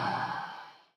HippoSnores-007.wav